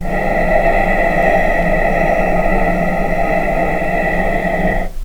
vc-E5-pp.AIF